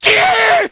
zombie_scream_4.wav